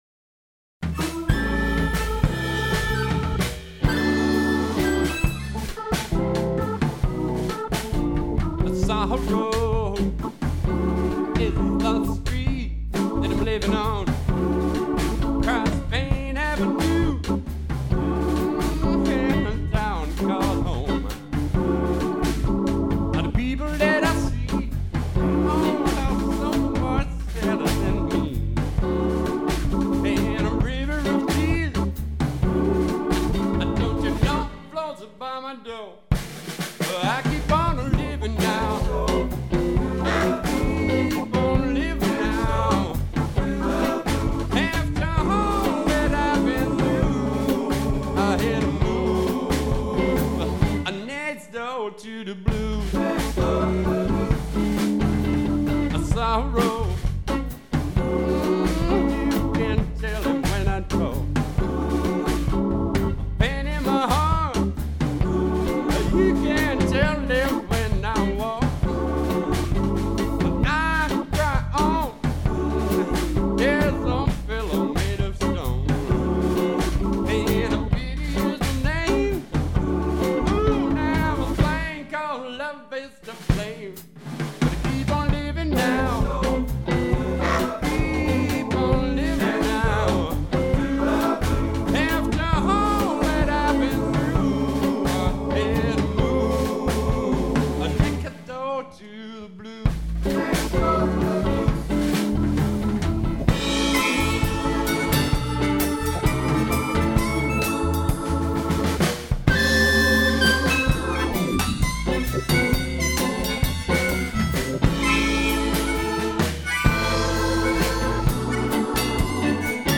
Jump Blues Band
bass
drums
keyboard / vocals
guitar / vocals